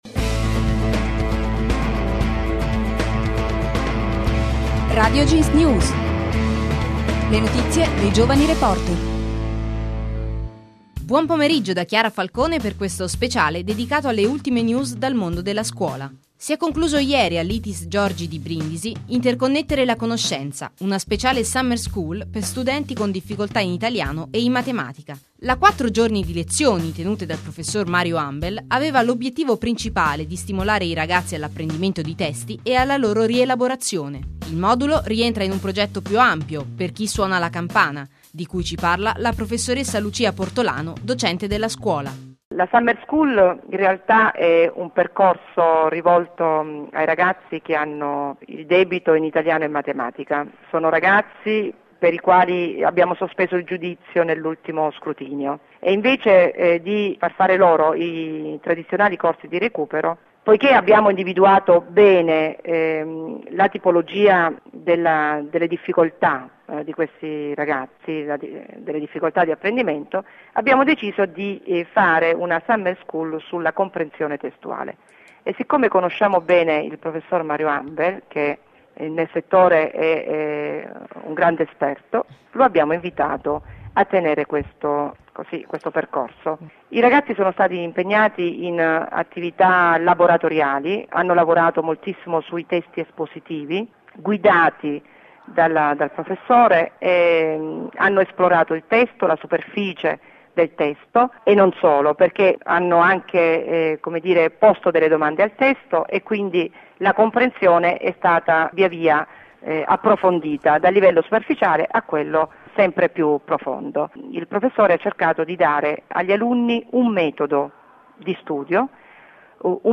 Le notizie dei giovani reporter